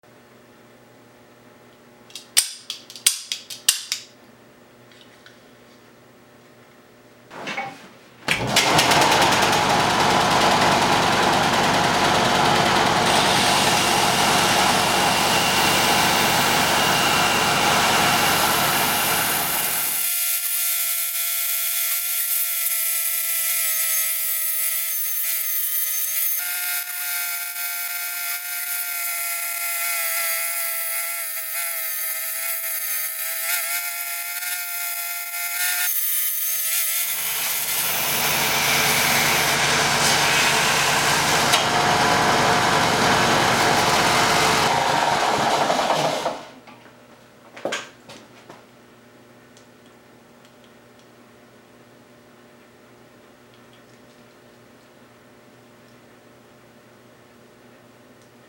Sanding a capgun down in a belt sander!